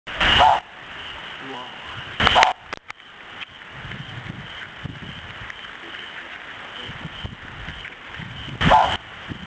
Mantanani Scops-Owl
Otus mantananensis
MantananiScopsOwl.mp3